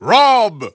The announcer saying R.O.B.'s name in English releases of Super Smash Bros. Brawl.
R.O.B._English_Announcer_SSBB.wav